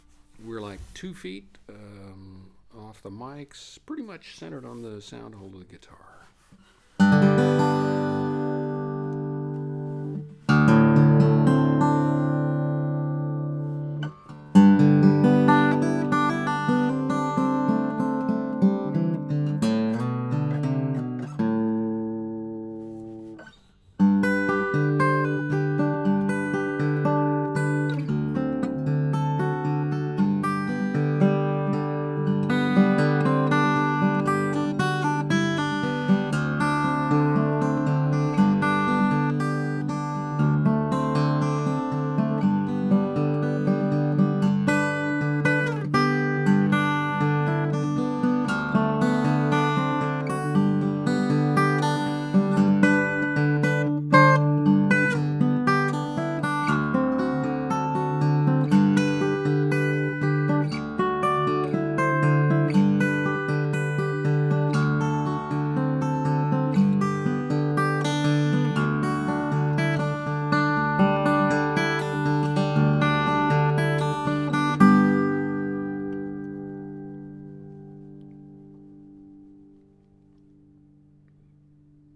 Naturally, I set the mics up as close as possible to the same position, using the same stereo arrangement.
Based on this test, it seems that a little digital EQ can make a Rode NT4 sound like a Schoeps CMC64 when recording me playing solo acoustic guitar (in this room on this day).
One of these is the Schoeps, one is the Rode without EQ, the other is the Rode with EQ.